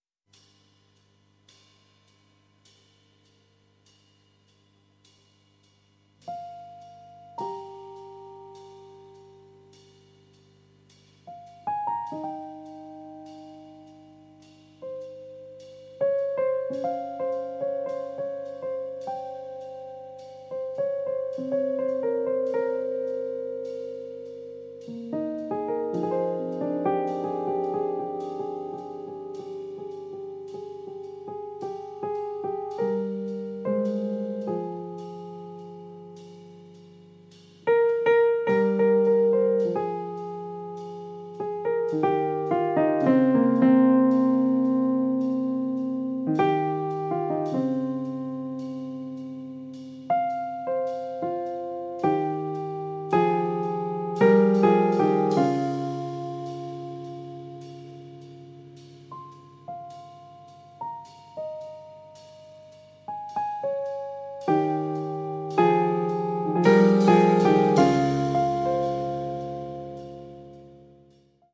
piano
contrabbasso e basso elettrico
batteria